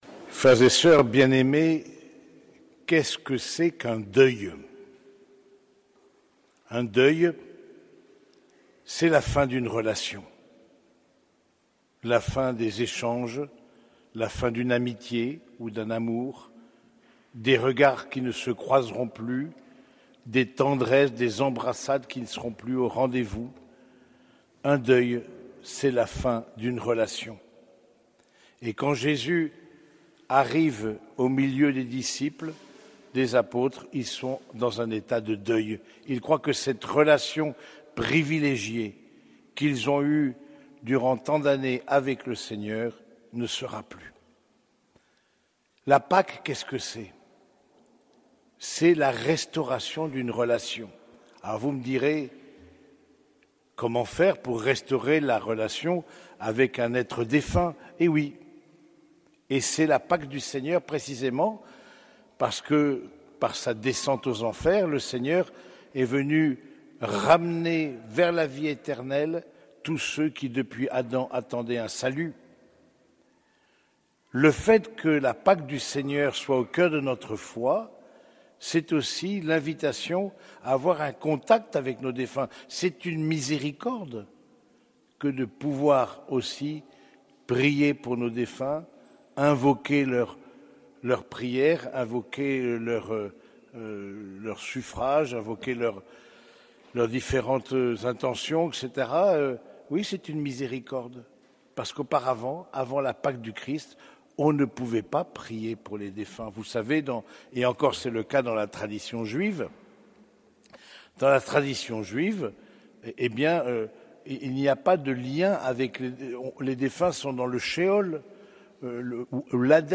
Homélie du deuxième dimanche de Pâques - Dimanche de la miséricorde